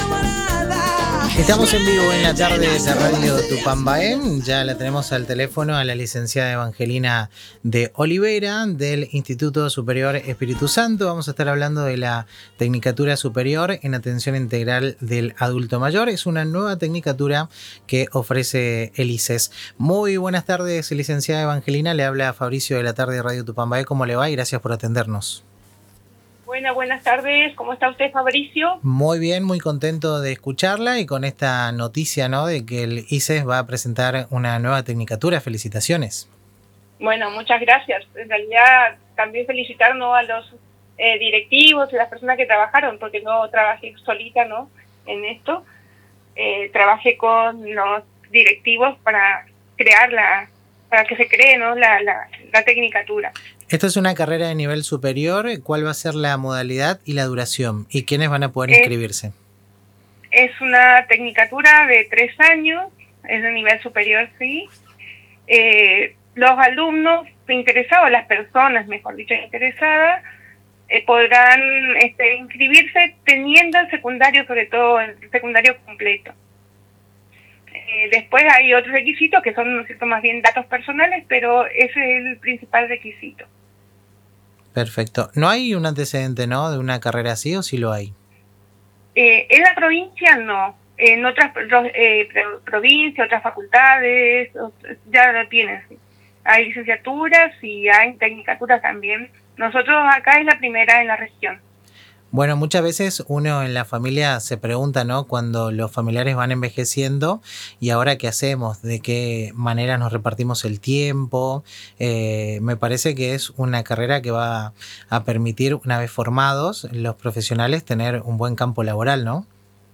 En diálogo con El Ritmo Sigue por Radio Tupambaé